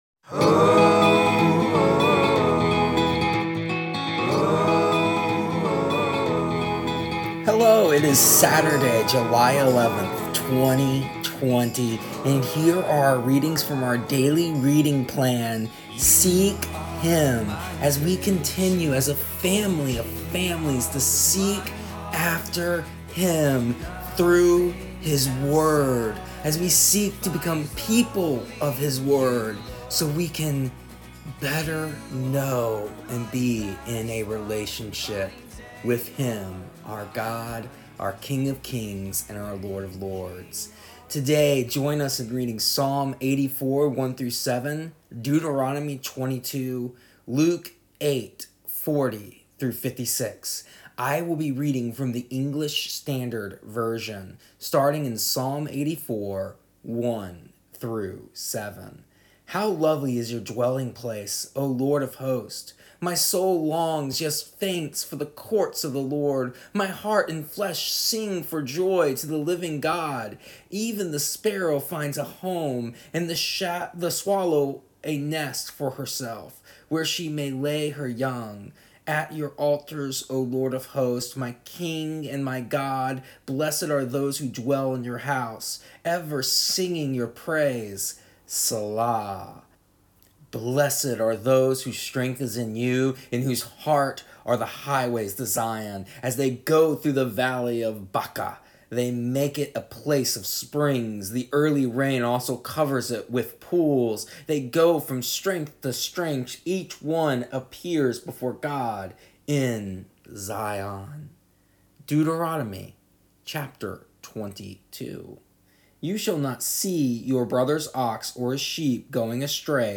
Here is an audio version of our daily readings from our daily reading plan Seek Him for July 11th, 2020. Ever wonder where the rules to lost and found where established in society?